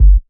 KICK II.wav